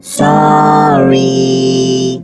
rick_kill_vo_05.wav